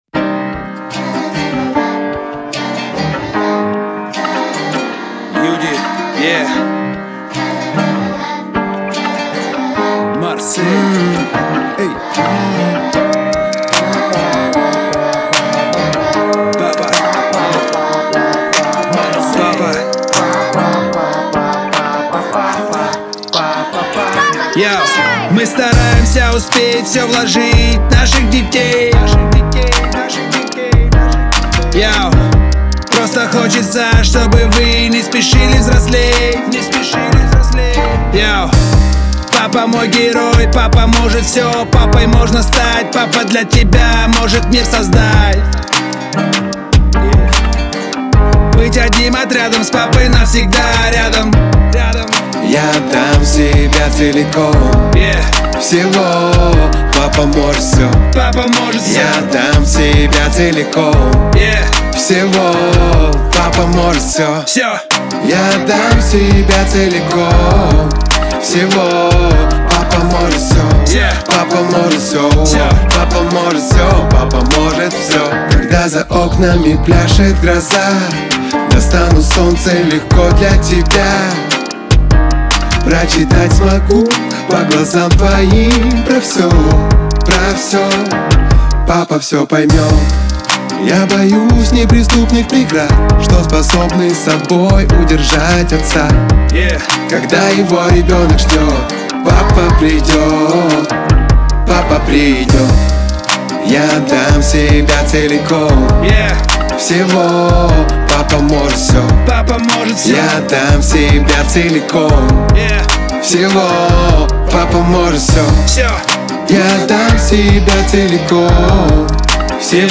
это яркая и энергичная композиция в жанре хип-хоп